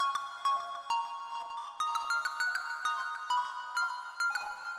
• Grain Deep Texture Melody 100 bpm.wav
Grain_Deep_Texture_Melody_100_bpm__g2i.wav